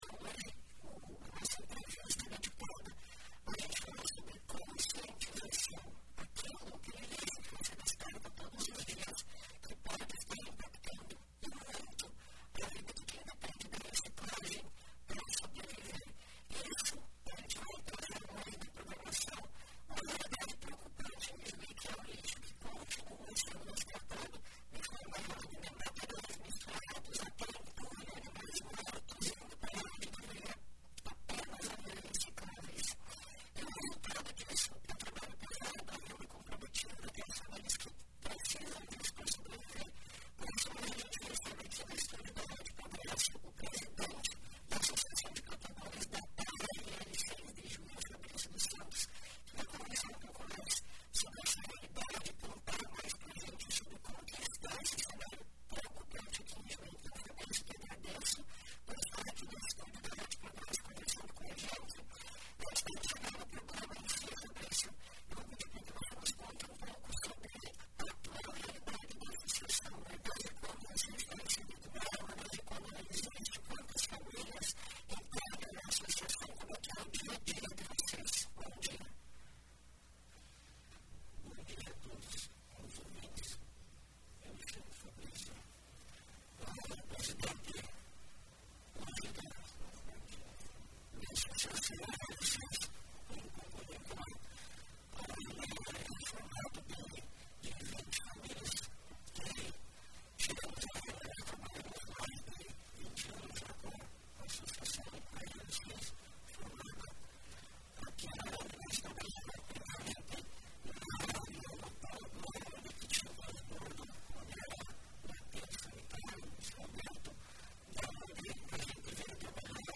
Conforme relatou em entrevista nesta quarta-feira (15), em muitos casos, o trab